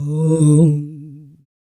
41 RSS-VOX.wav